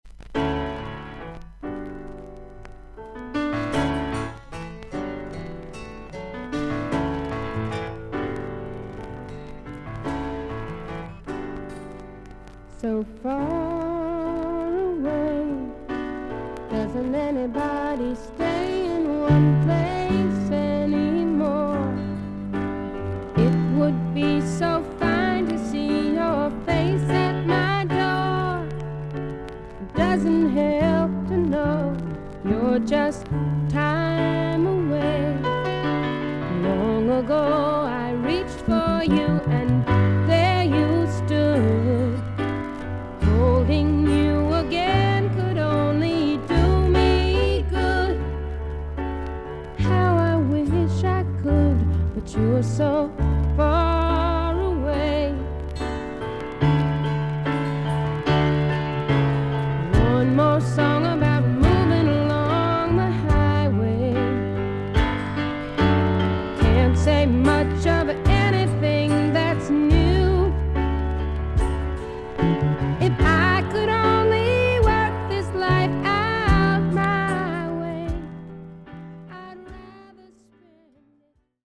イギリス盤 / 12インチ LP レコード / ステレオ盤
少々軽いパチノイズの箇所あり。少々サーフィス・ノイズあり。クリアな音です。
女性シンガー／ソングライター。